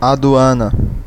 Ääntäminen
Ääntäminen France: IPA: [dwan] Tuntematon aksentti: IPA: /dwɛn/ Haettu sana löytyi näillä lähdekielillä: ranska Käännös Ääninäyte Substantiivit 1. aduana {f} BR 2. alfândega {f} Suku: f .